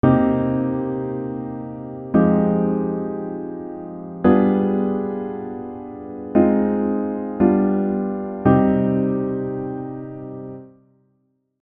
Cm7 - Fm9 - G7b9 - Ab6 - G7 - Cm
Why it's emotional: At its core, this is a straightforward i - iv - V - i progression.